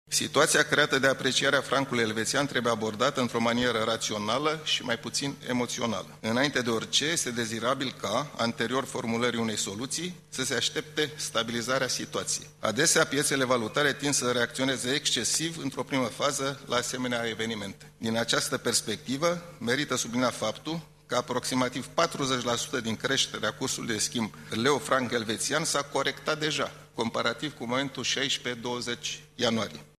Mugur Isărescu se află în faţa comisiilor reunite de buget-finanţe, în contextul în care Parlamentul analizează proiecte de legi care să vină în sprijinul celor peste 70 de mii de români cu credite în franci elveţieni.
Mugur Isărescu a reluat astăzi, în Parlament, apelul la calm şi la soluţii bine gândite: